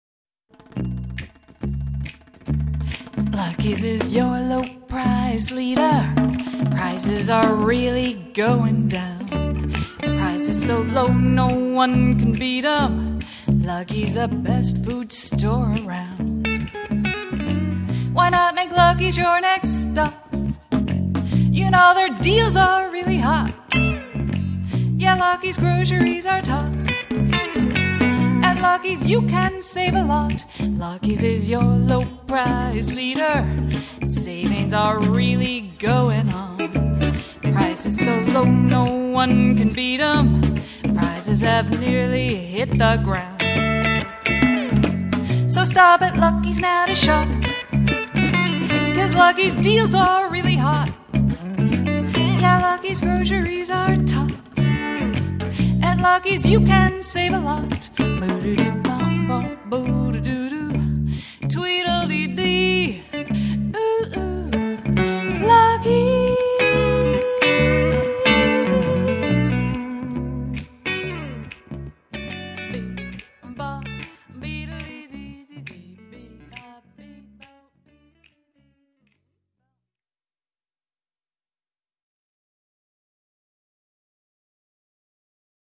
Guitar
Percussion